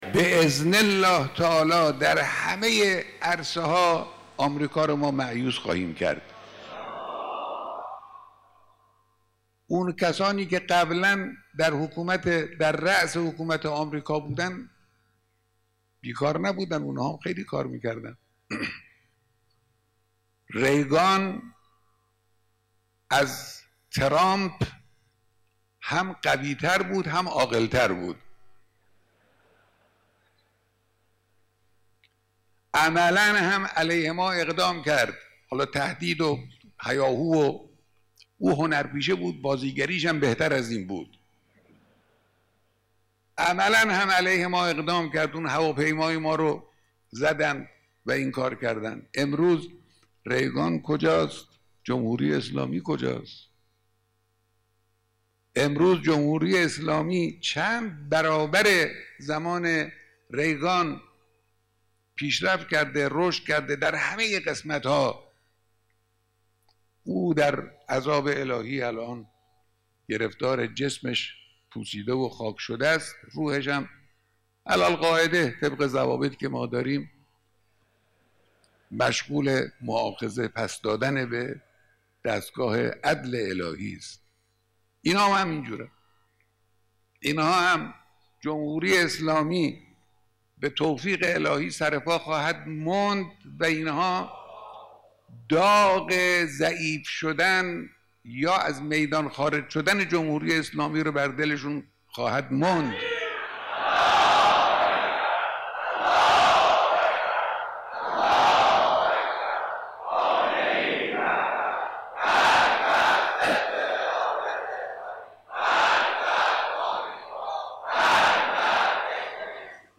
بخشی از بیانات رهبر انقلاب در دیدار با اعضای شورای هماهنگی تبلیغات اسلامی